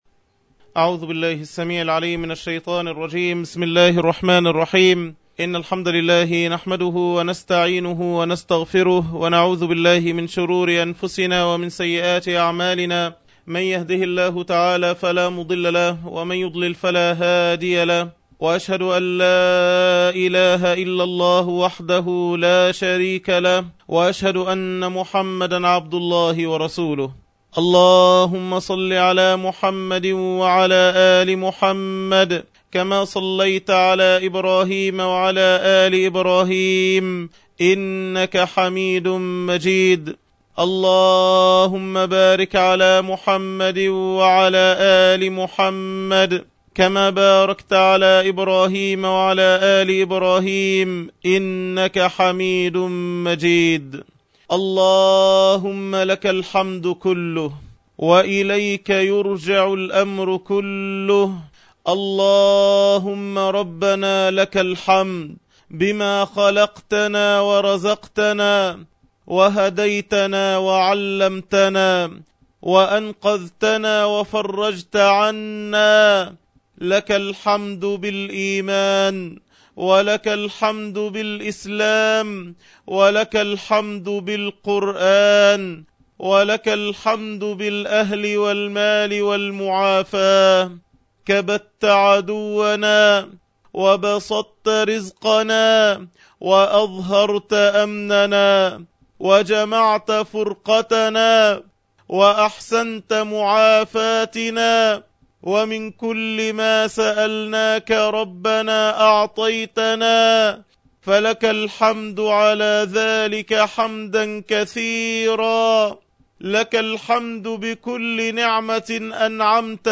تفاصيل المادة عنوان المادة الدرس الثالث(شرح الاذكار) تاريخ التحميل السبت 30 يونيو 2012 مـ حجم المادة 15.44 ميجا بايت عدد الزيارات 1,284 زيارة عدد مرات الحفظ 608 مرة إستماع المادة حفظ المادة اضف تعليقك أرسل لصديق